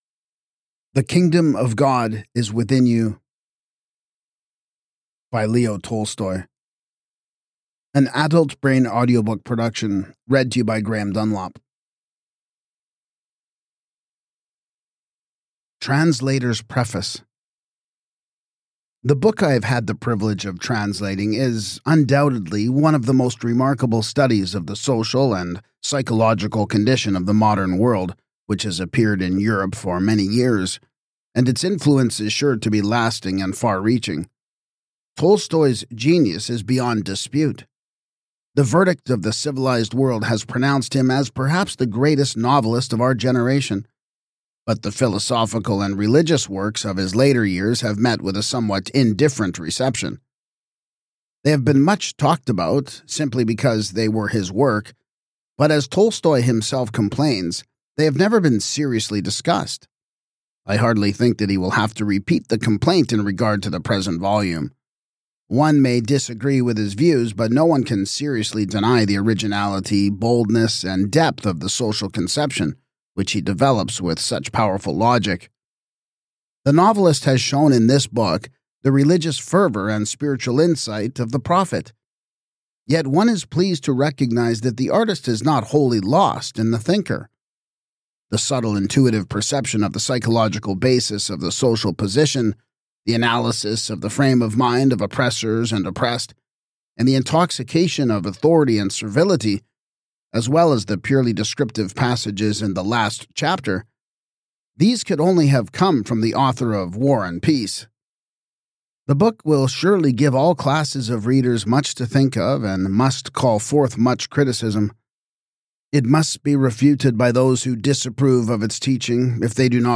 His radical rejection of organized religion and state violence inspired generations of reformers, from Mahatma Gandhi to Martin Luther King Jr. This audiobook brings Tolstoy’s prophetic voice to life, offering timeless wisdom for anyone seeking freedom from oppression, hypocrisy, and the empty promises of worldly power.